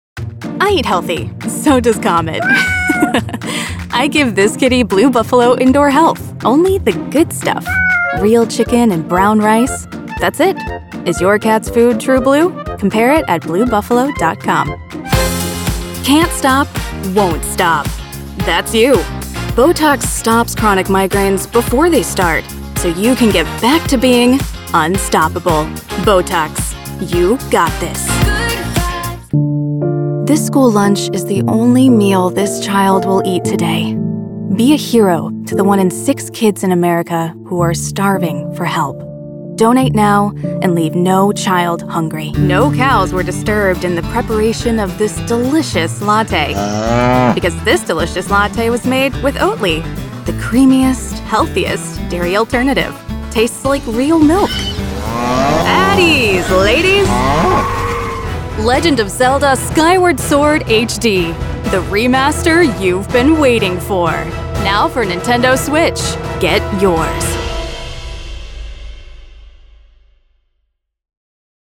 Voiceover Artist,
Sex: Female
Accents: Standard American
Ages Performed: Teen, Young Adult,
Voiceover Genre: Commercial, Corporate, E-Learning, Explainer,
Studiobricks booth, Sennheiser 416 mic, Sennheiser MK8 mic, Adobe Audition
Her voice is described as bright, airy, and engaging.